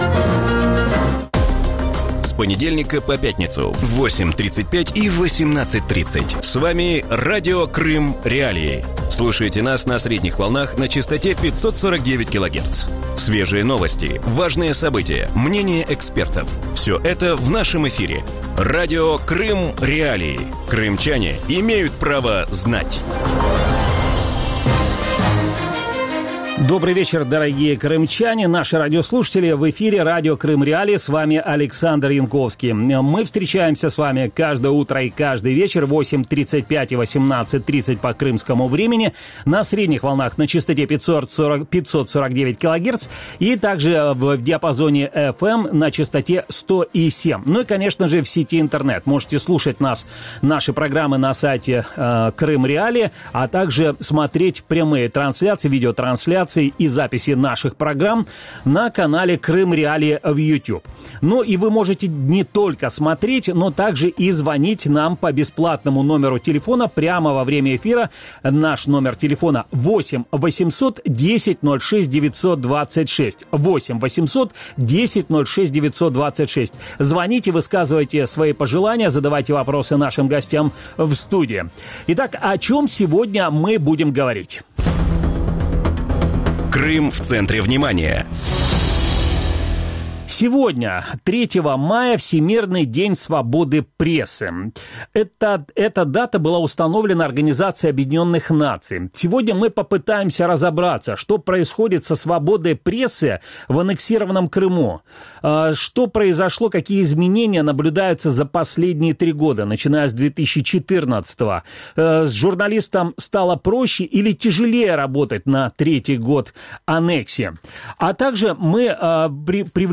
В вечернем эфире Радио Крым.Реалии обсуждают ситуацию со свободой слова в Крыму.